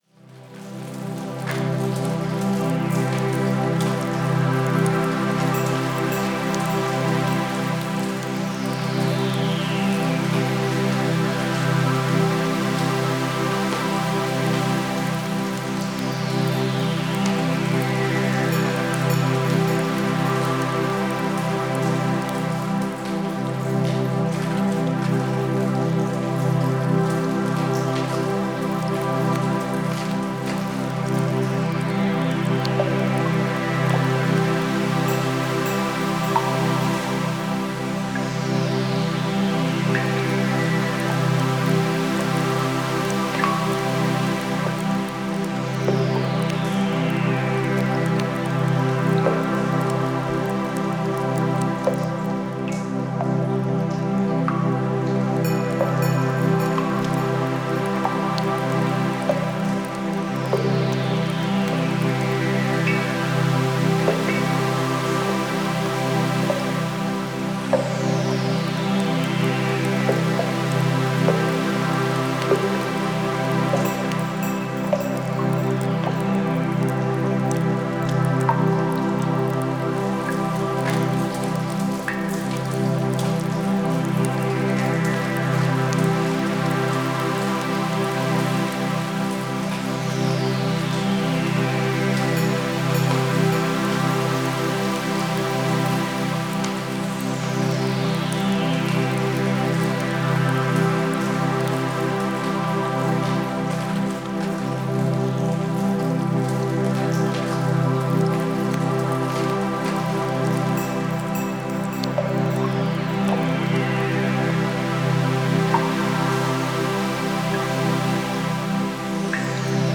Genre: Ambient, New Age.